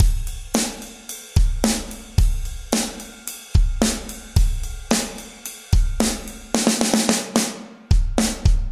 合唱与骑行
描述：桶的例子